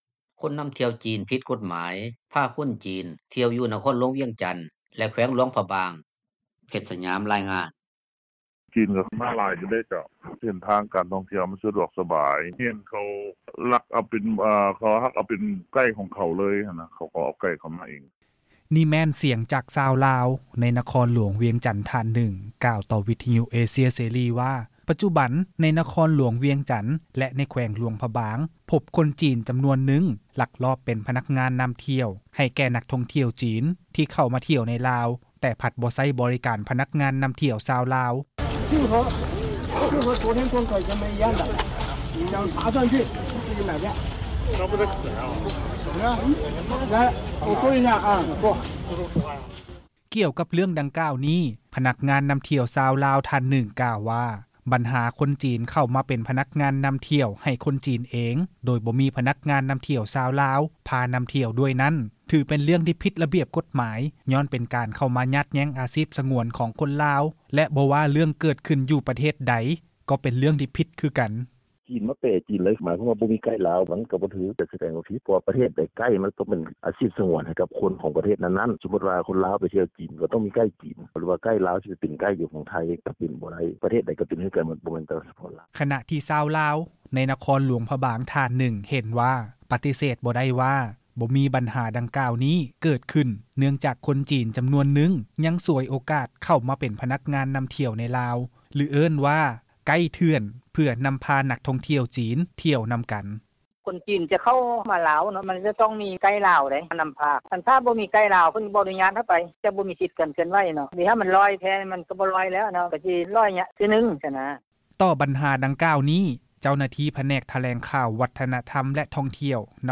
”ສຽງພະນັກງານນໍາທ່ຽວຈີນ ລົມກັບນັກທ່ອງທ່ຽວຈີນ ຢູ່ພະທາດຫຼວງວຽງຈັນ”